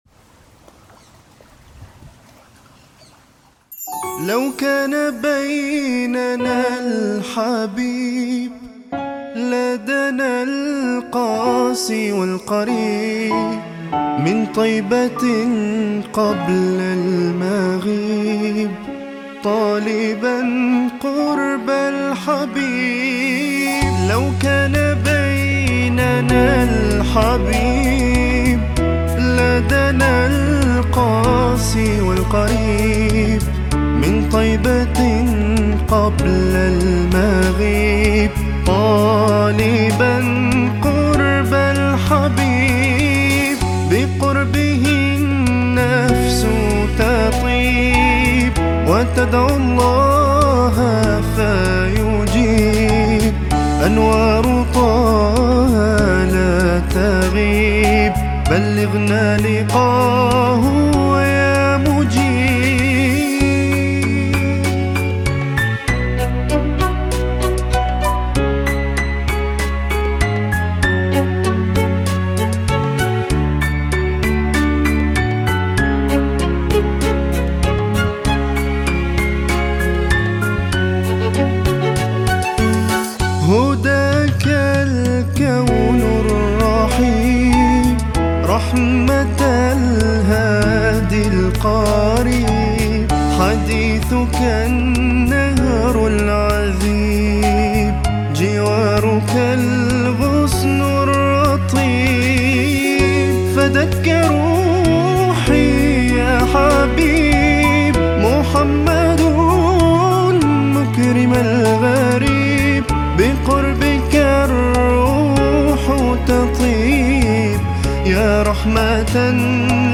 Arabic Songs , Nasyid Songs